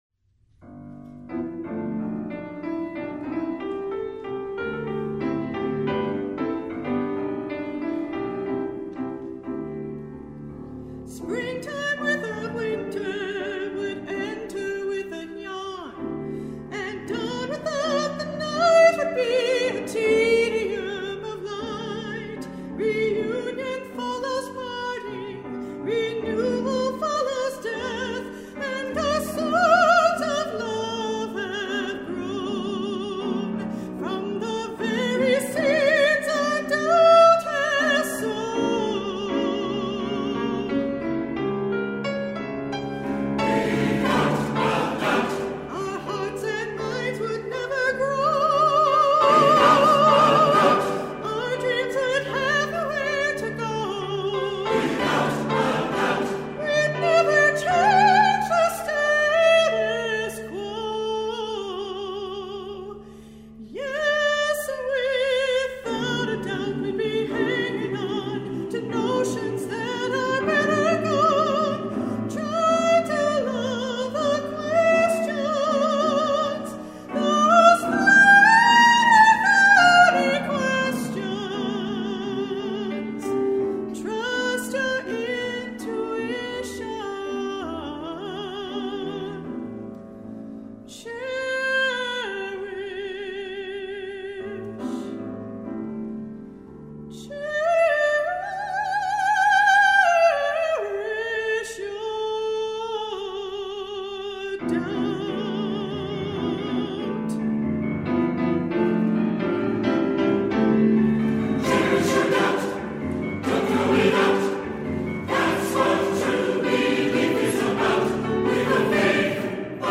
SATB, soloist, piano, opt. bass